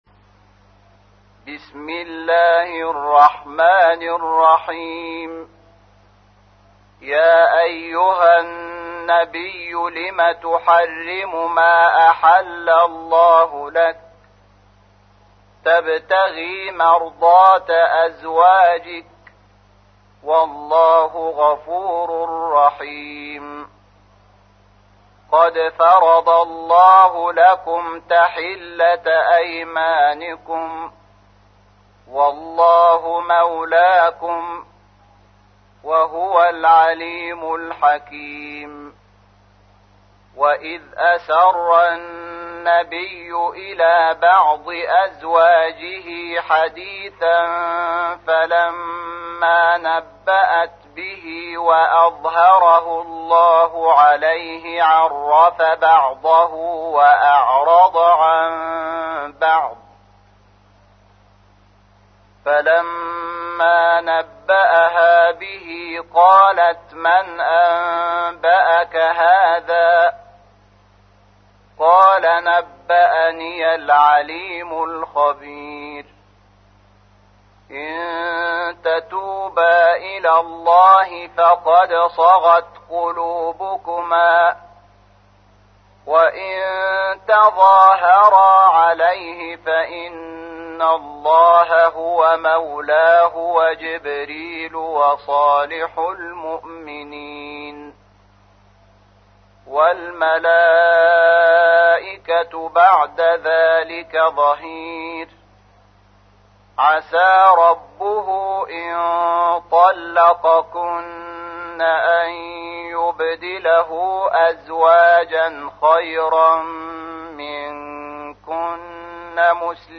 تحميل : 66. سورة التحريم / القارئ شحات محمد انور / القرآن الكريم / موقع يا حسين